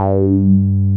RUBBER G3 M.wav